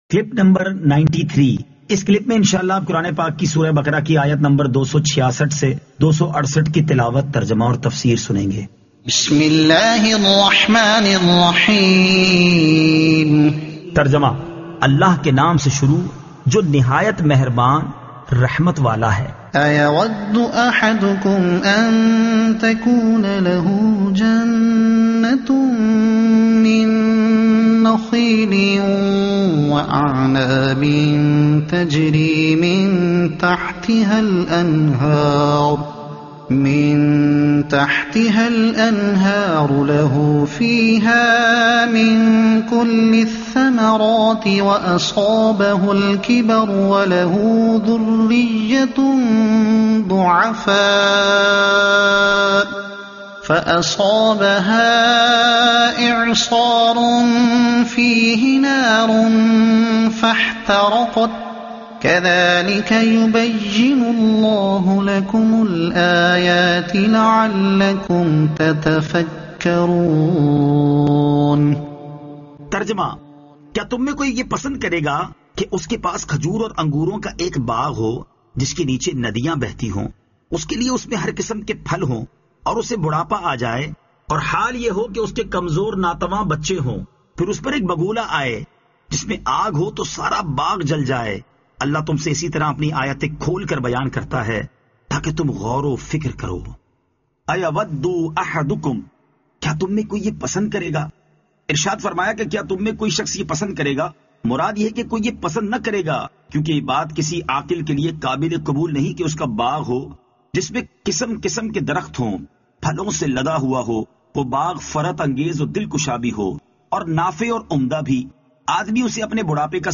Surah Al-Baqara Ayat 266 To 268 Tilawat , Tarjuma , Tafseer